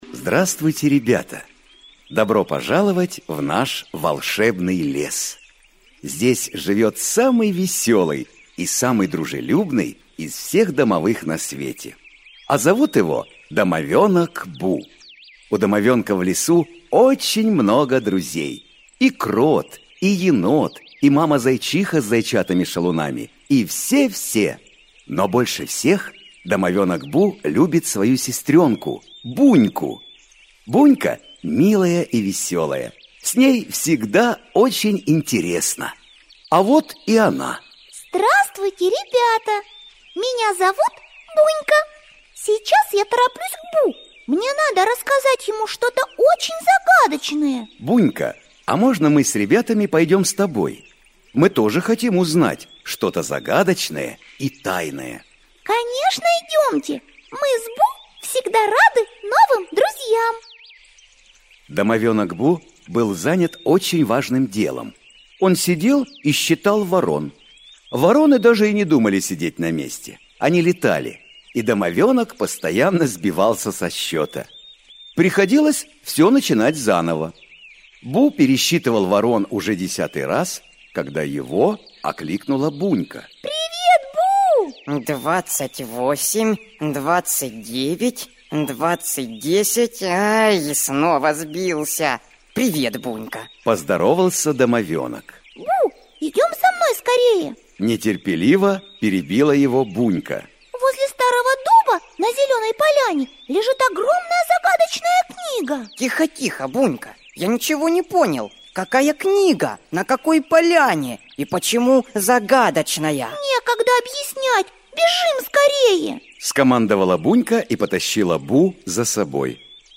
Аудиокнига Хочу все знать. В мире растений | Библиотека аудиокниг
В мире растений Автор Евгений Викторович Бульба Читает аудиокнигу Коллектив авторов.